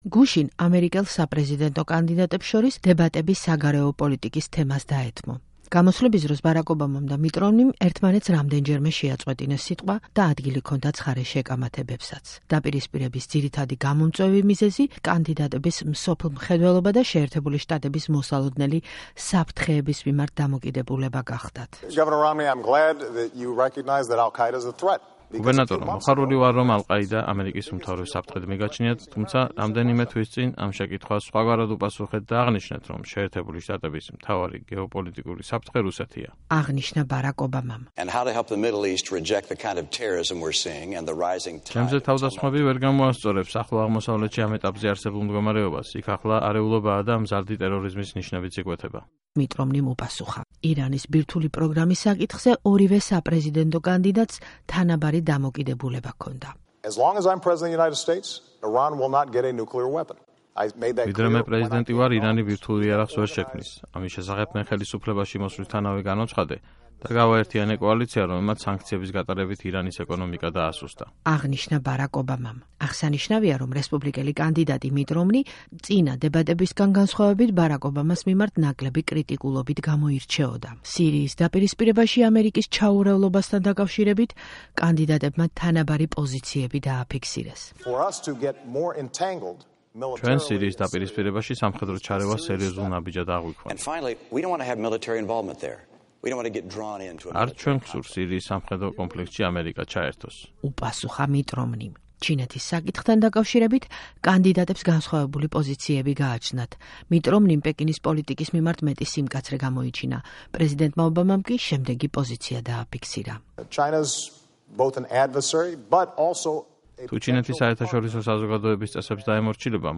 ბარაკ ობამამ და მიტ რომნიმ ერთმანეთს რამდენჯერმე შეაწყვეტინეს სიტყვა, ადგილი ქონდა ცხარე შეკამათებებსაც
დებატები